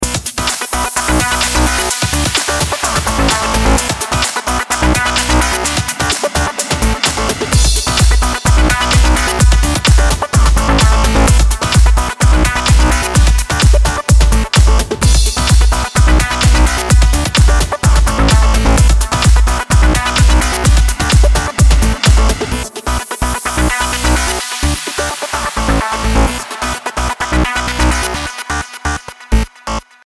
As we now listen back to the sample song, you will hear that the synth sounds a lot cleaner, yet is more present in the mix.
Mattertone_Synth_Shaping_Wet.mp3